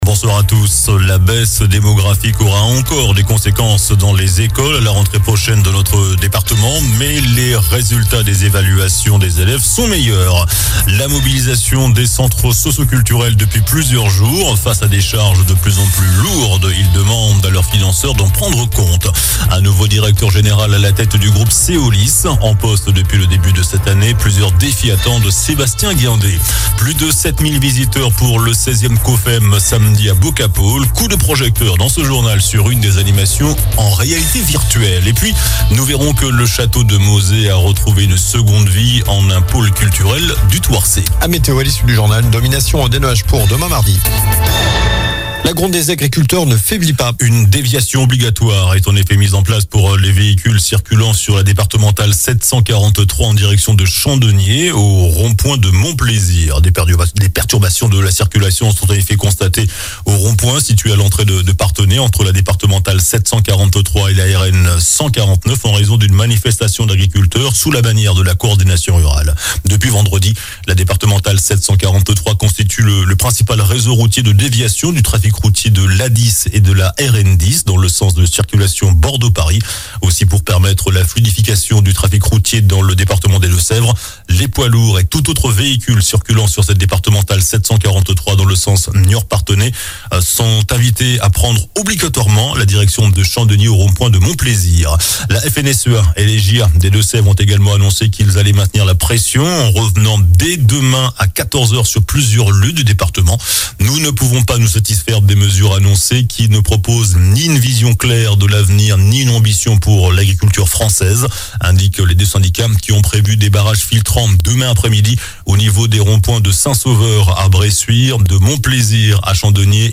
JOURNAL DU LUNDI 29 JANVIER ( SOIR )